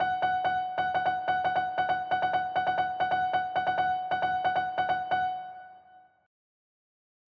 • Качество: 128, Stereo
без слов
на уведомление
Азбука Морзе